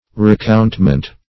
Recountment \Re*count`ment\ (-ment), n.